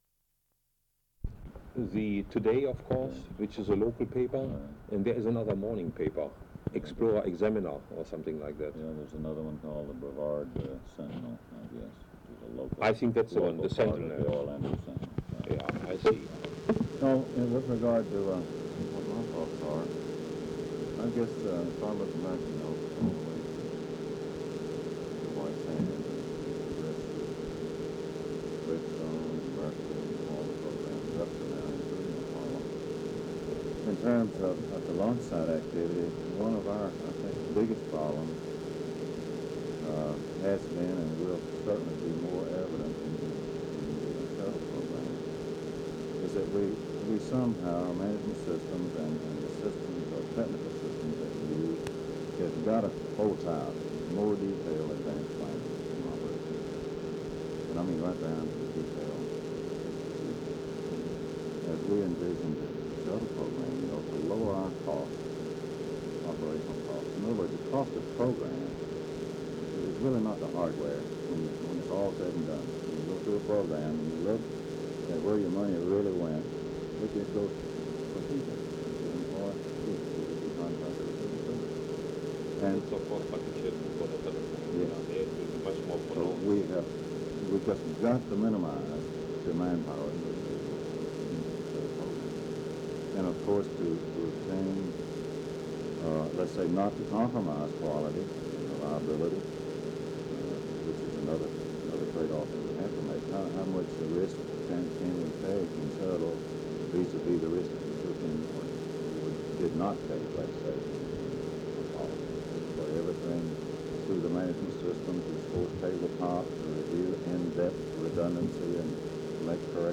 Description Wernher von Braun conversing with multiple people on the management of Saturn/Apollo, Skylab, and Shuttle programs.
Interviews
Audiocassettes